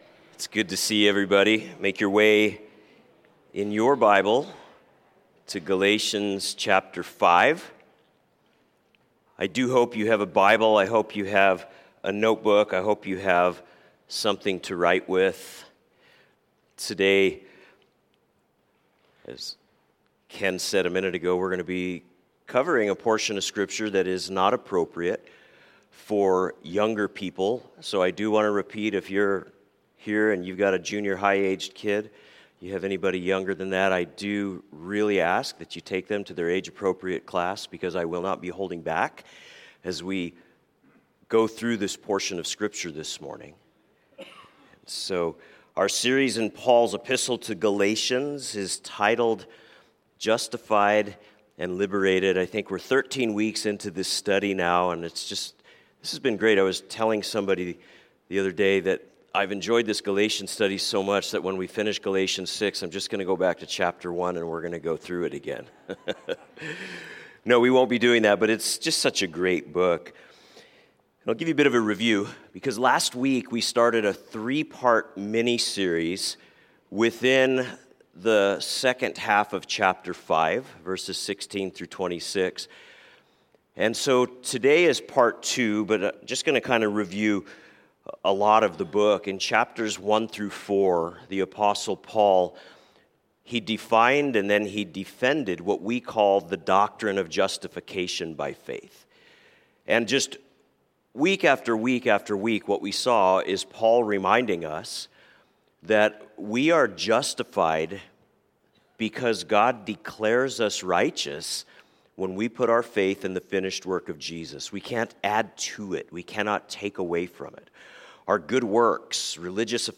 A message from the series "Sunday Morning."